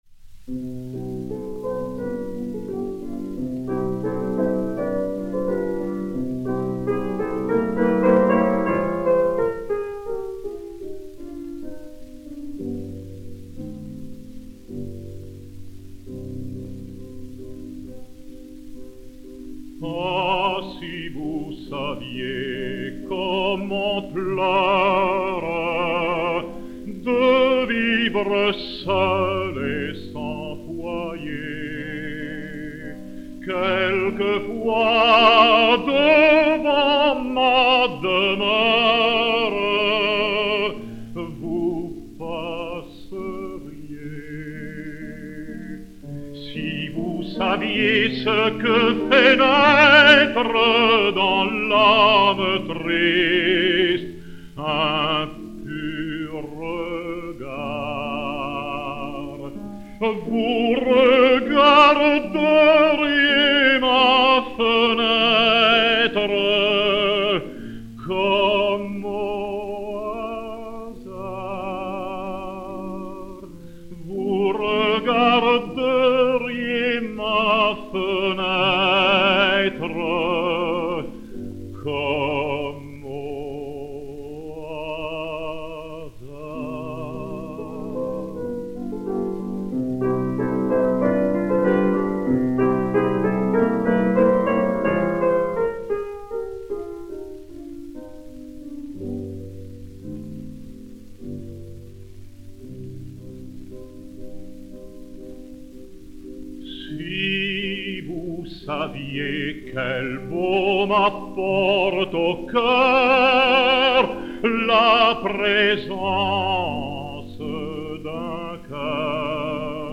Mélodie
piano